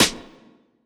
Waka SNARE ROLL PATTERN (85).wav